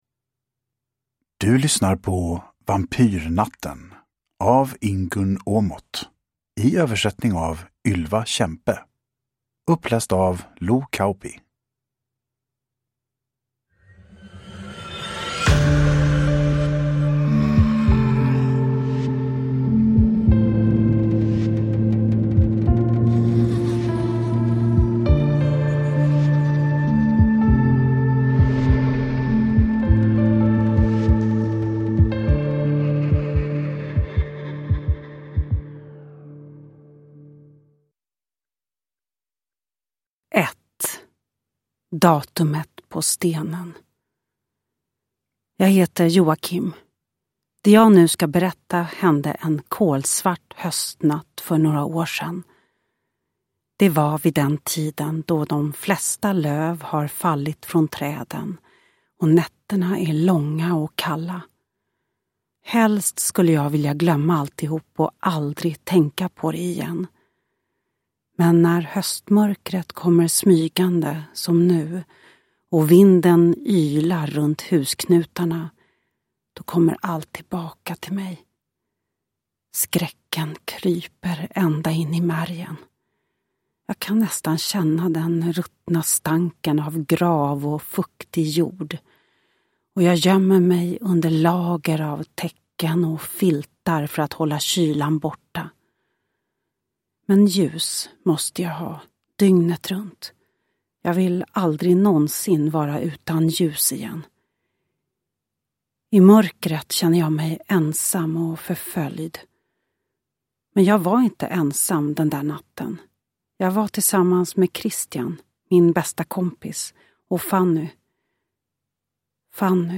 Vampyrnatten – Ljudbok – Laddas ner
Uppläsare: Lo Kauppi